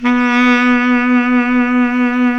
Index of /90_sSampleCDs/Roland L-CDX-03 Disk 1/SAX_Baritone Sax/SAX_40s Baritone
SAX B.SAX 0A.wav